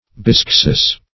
Search Result for " bisexous" : The Collaborative International Dictionary of English v.0.48: Bisexous \Bi*sex"ous\, a. [L. bis twice + sexus sex: cf. F. bissexe.]